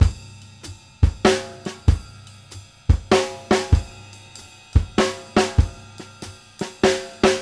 CYM GROO.1-L.wav